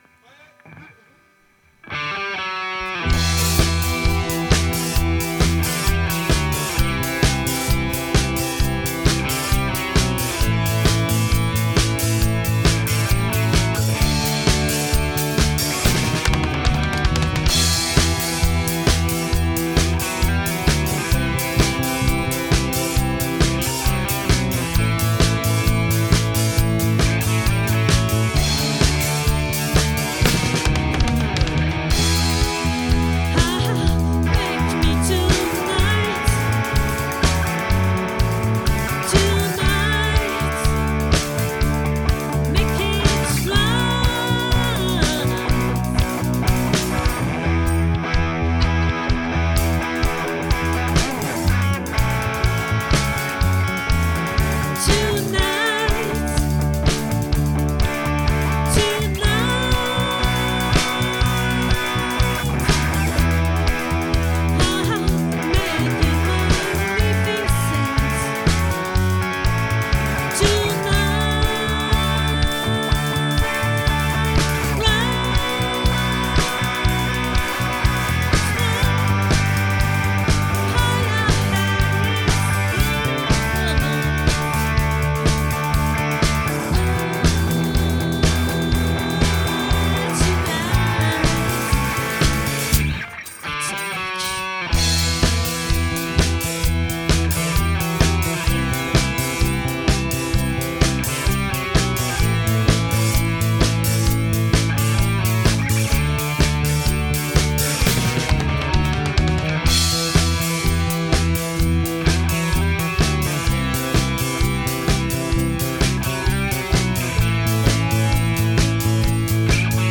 🏠 Accueil Repetitions Records_2025_12_08